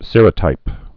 (sîrə-tīp, sĕrə-)